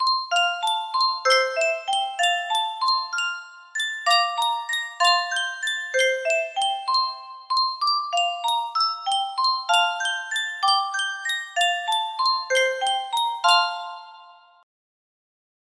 Yunsheng Boite a Musique - Plaisir d'Amour 6752 music box melody
Full range 60